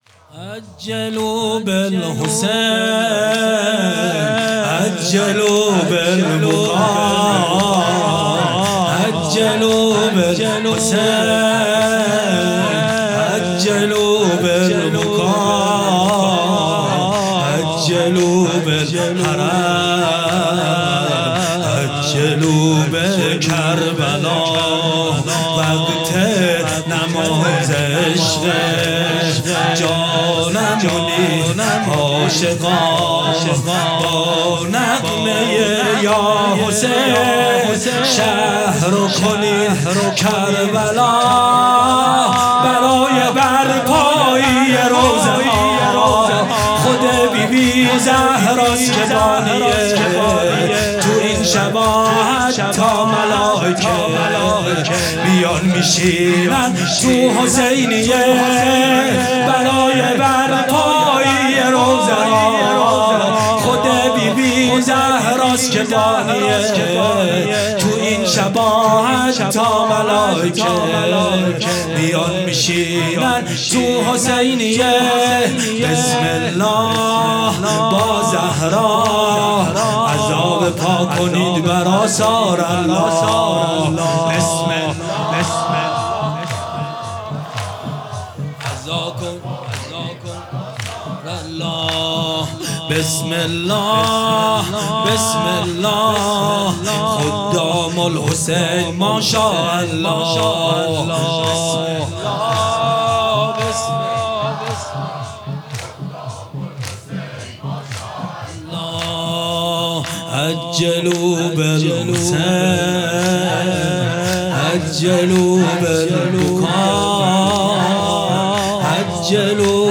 هیئت محبان الحسین علیه السلام مسگرآباد